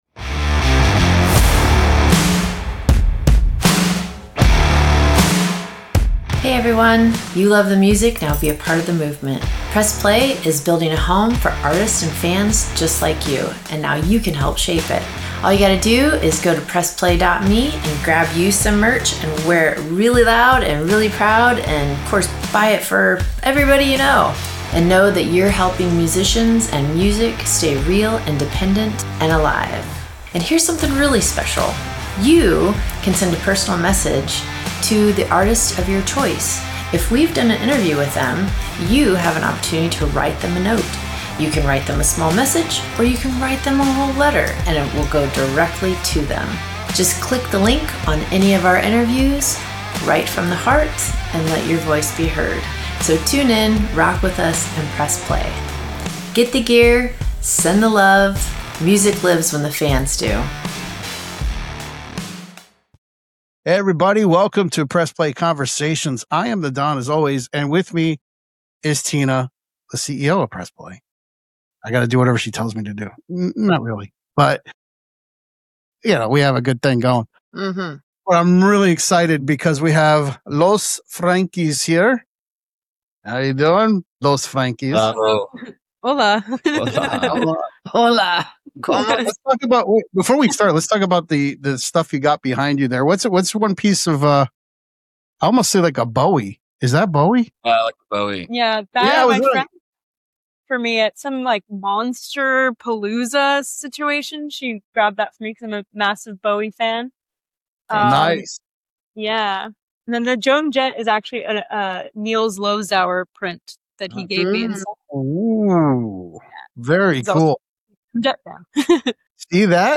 There’s fuzz in the guitars, but it’s controlled. There’s punk energy, but it’s sharpened by restraint.